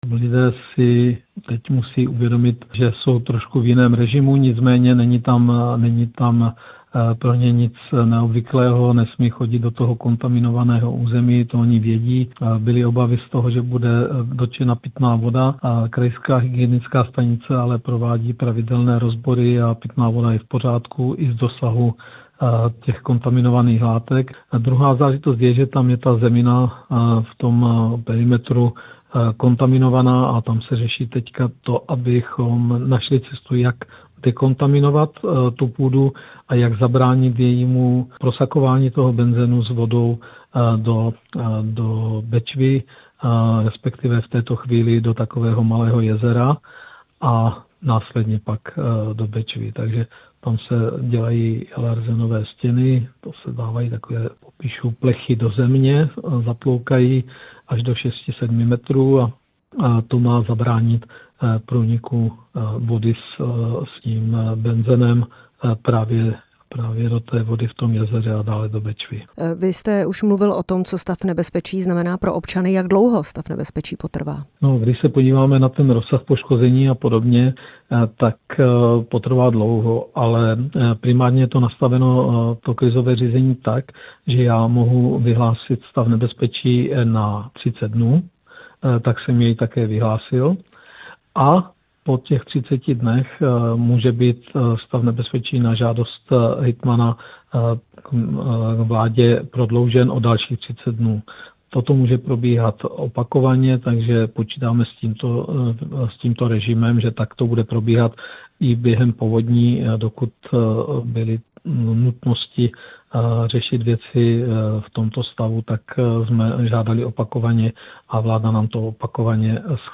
Ptali jsme se ve vysílání Rádia Prostor.
Rozhovor s olomouckým hejtmanem Ladislavem Oklešťkem